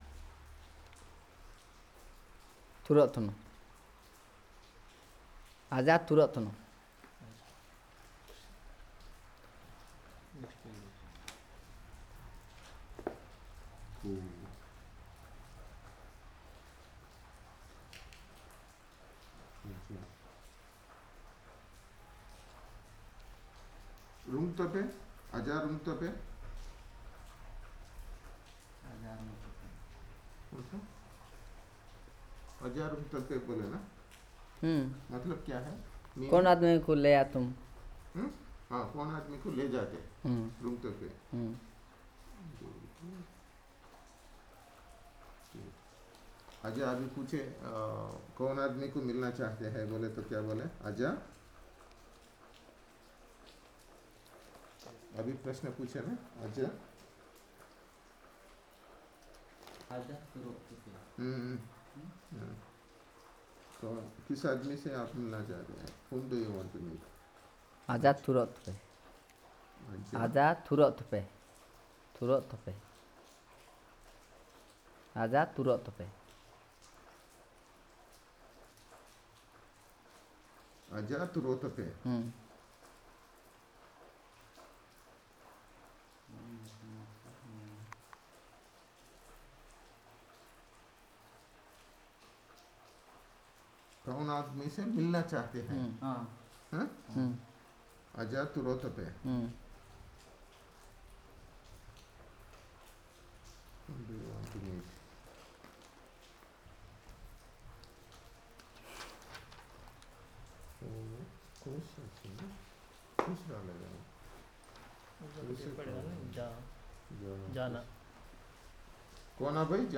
Elicitation of words on interrogatives